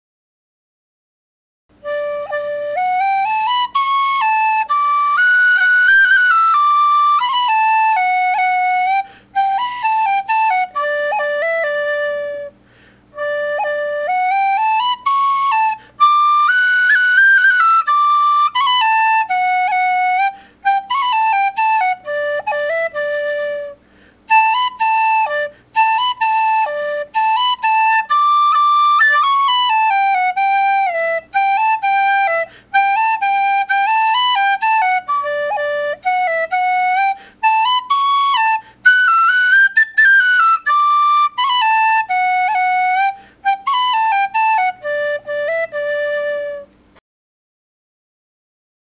Bottom Line: Moderately loud, nice wooden sound.
It’s got a very pure sound to it, with no attack chiff (that “ch” sound at the start of a note)and a very slight amount of note chiff (the white noise within a note’s duration).
Sound clips of the whistle: